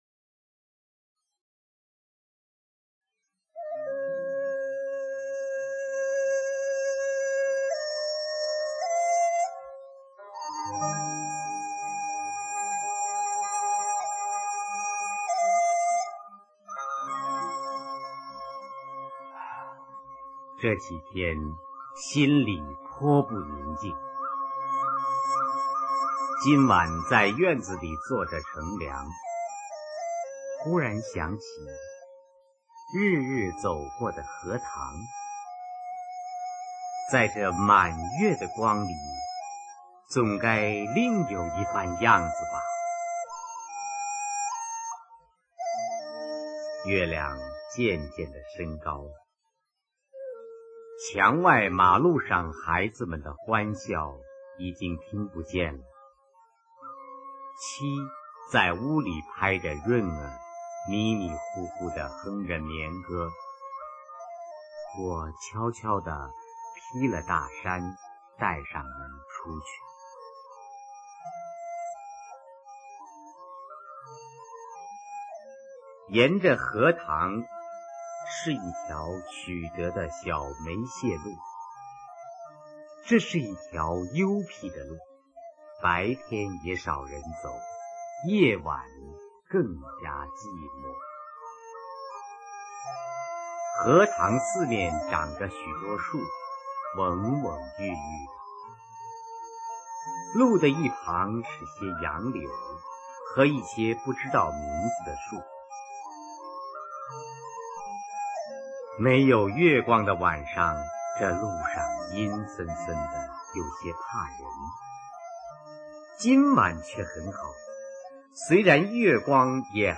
[24/2/2009]著名艺术家董行佶朗诵欣赏之三《朱自清散文 荷塘月色》 激动社区，陪你一起慢慢变老！